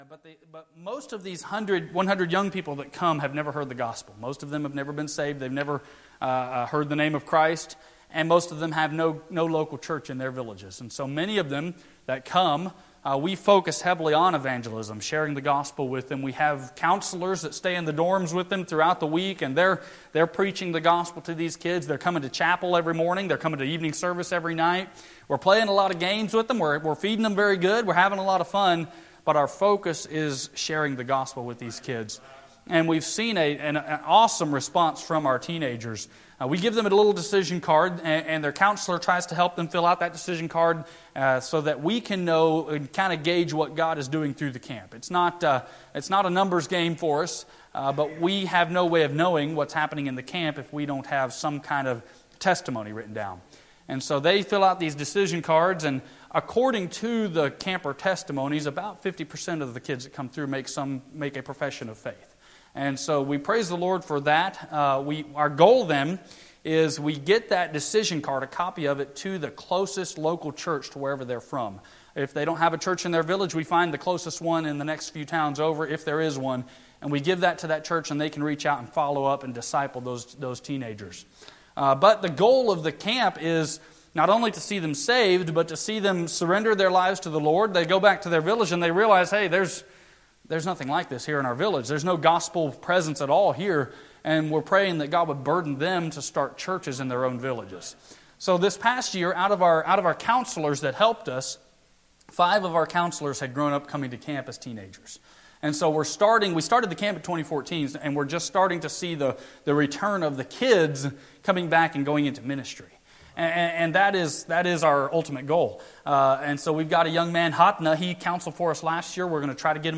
SERMON AND TEACHING AUDIO: